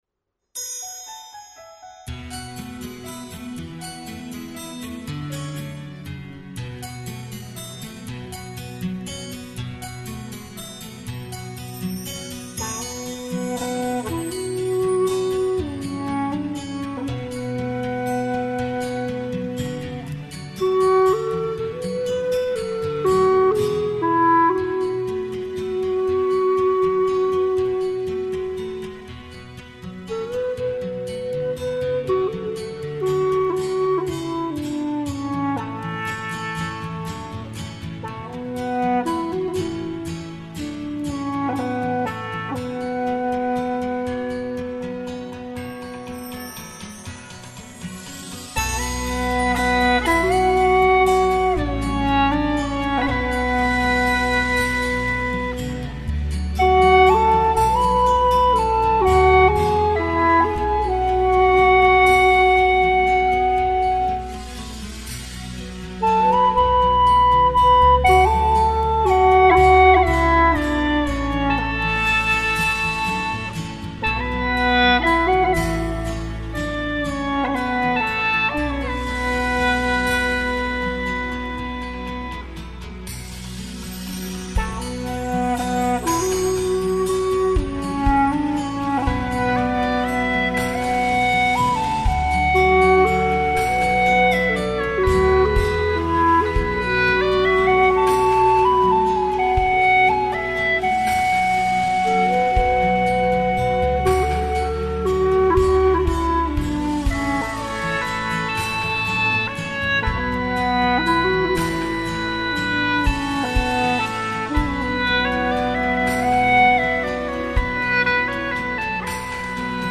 苏格兰民歌 演奏
调式 : D 曲类 : 流行 我要学习此曲 点击下载 《斯卡布罗集市》诉说了一个凄美的爱情故事：一个参军的男青年远离自己相爱的姑娘，在战争中不幸遇难，但长满芫荽、鼠尾草、迷迭香和百里香的村庄，散发着爱的芬芬，闪烁着生命的呼唤。。。